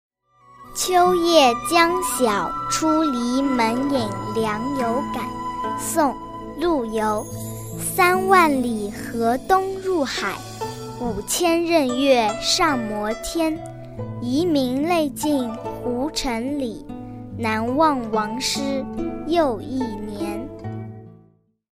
朗读：秋夜将晓出篱门迎凉有感.mp3